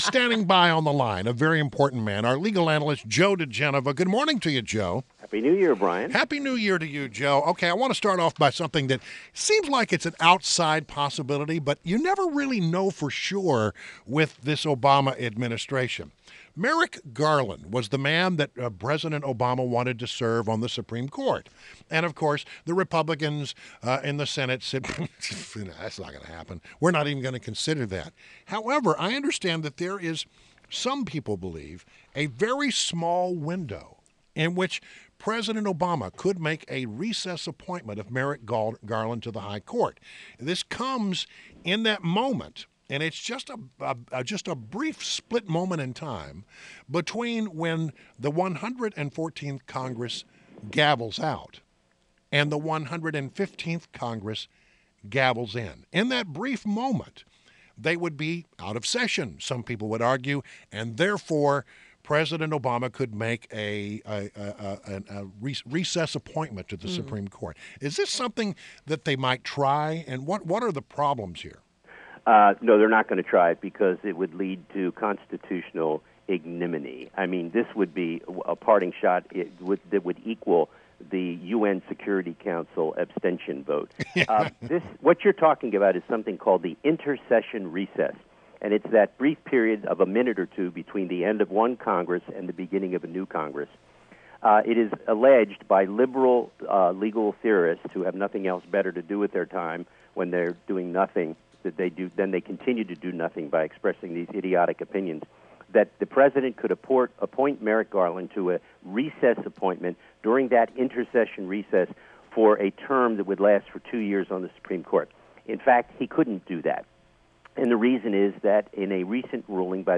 INTERVIEW – JOE DIGENOVA – legal analyst and former U.S. Attorney to the District of Columbia